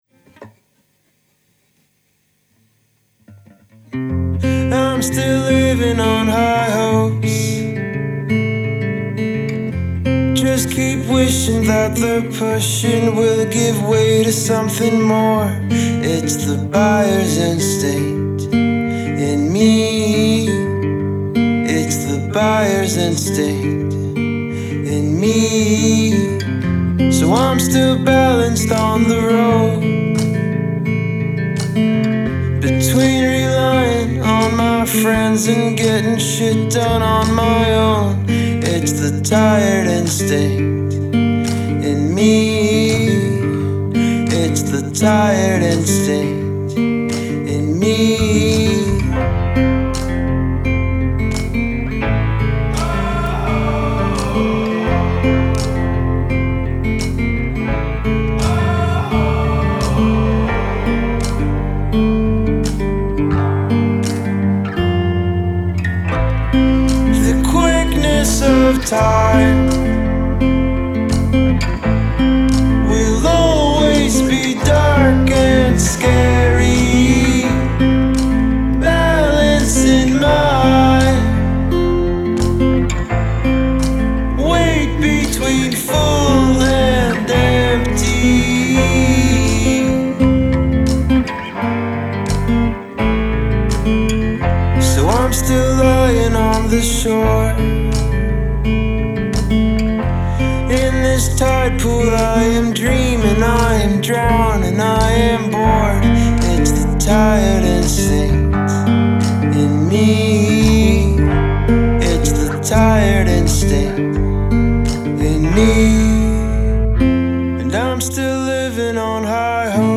An incredibly pleasing and heartwarming acoustic/folk track
starts off very small and becomes epic in its last minute